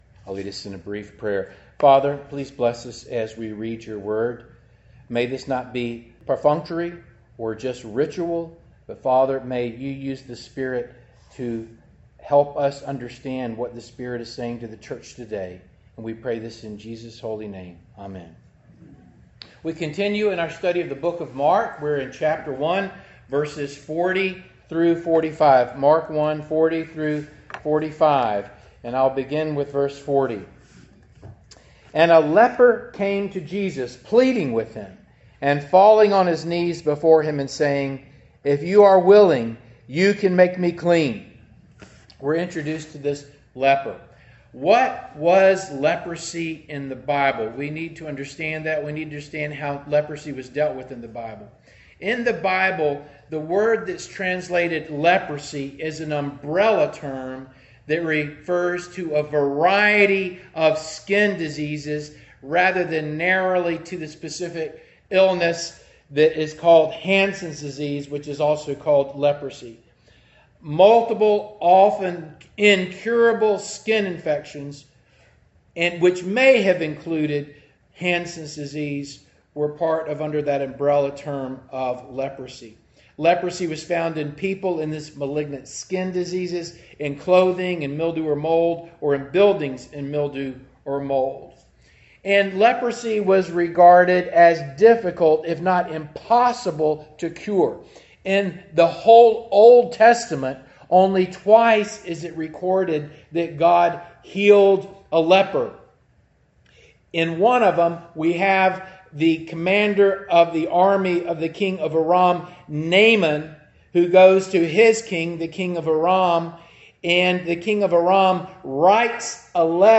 Mark Passage: Mark 1:40-45 Service Type: Morning Service Download Files Bulletin « “‘He Healed Many'” “‘Son